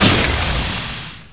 smoke.wav